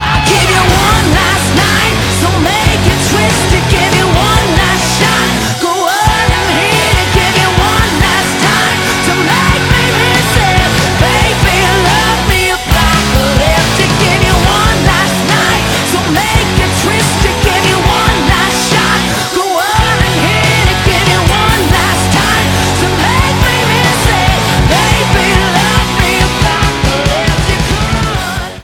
• Качество: 192, Stereo
американской рок-группы с хорошим женским голосом